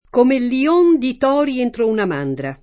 leone
leone [le1ne] s. m. — ant. o poet. lione [li-1ne]: Come lion di tori entro una mandra [